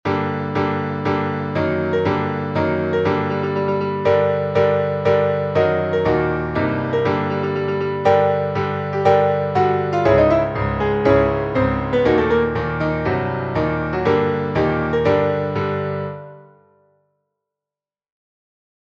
piano version.